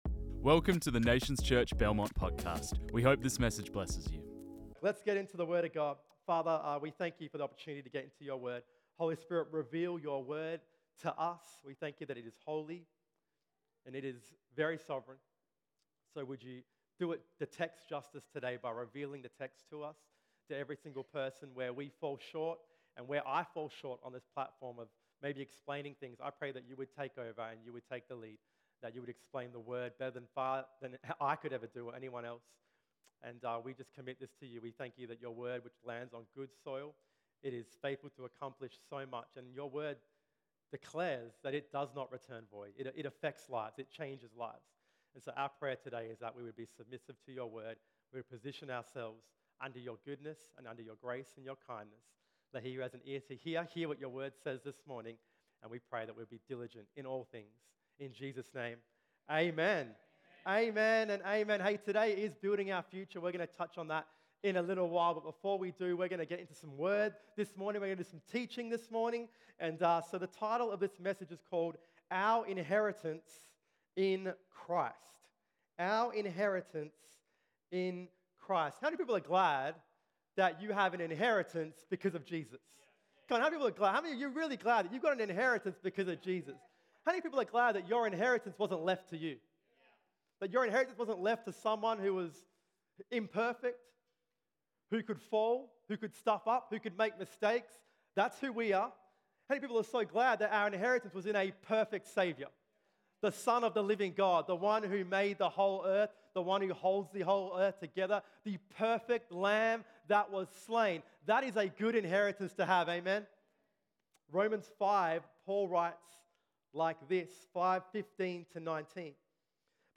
This message was preached on 11 June 2023.